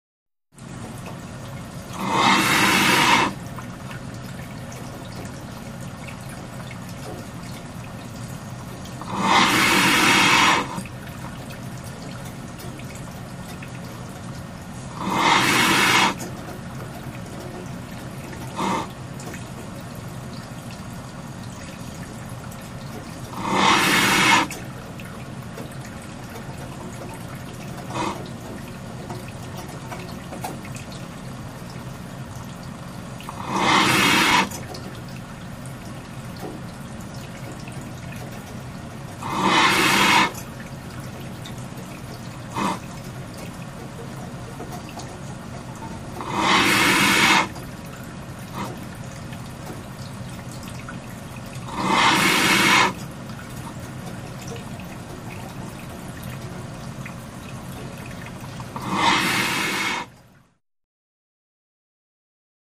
Water Filtration Room; Repeated Oxygen Pumps; Water Trickling In Background, Clanks; Close Perspective.